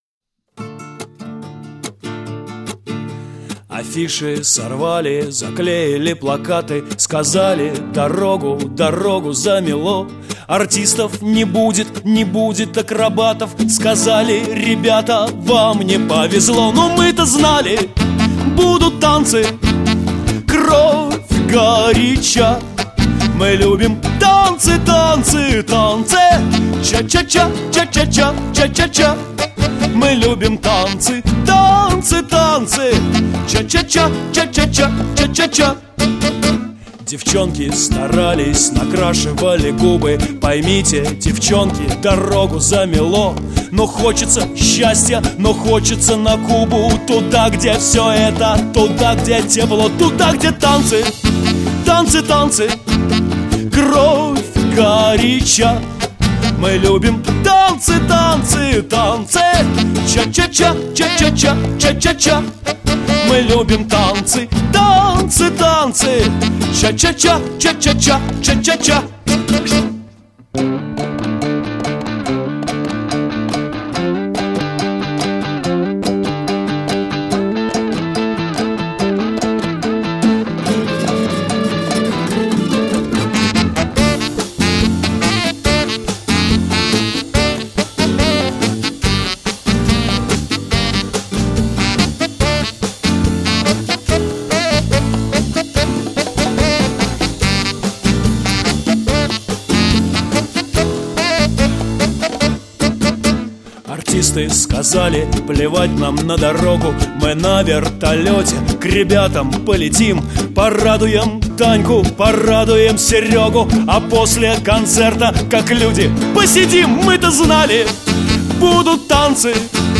был записан вживую